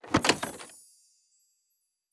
Prize Chest (1).wav